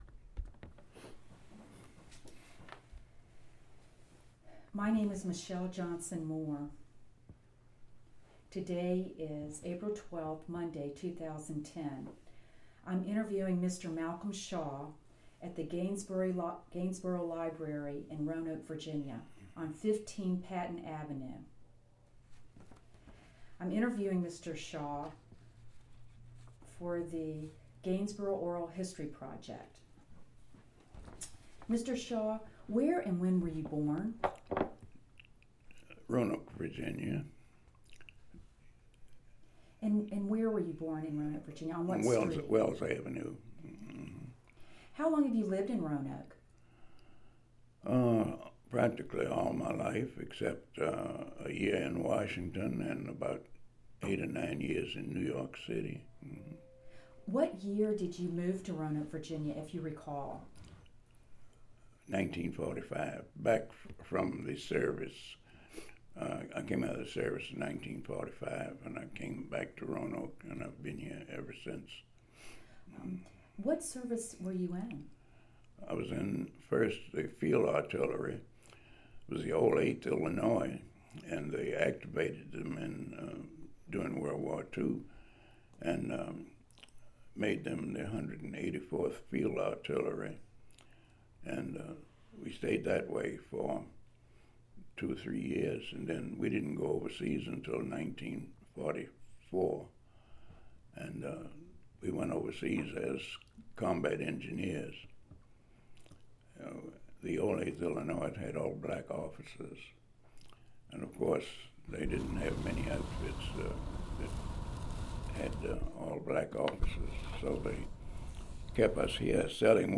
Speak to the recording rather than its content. Location: Gainsboro Branch Library